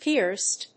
/píɚst(米国英語), píəst(英国英語)/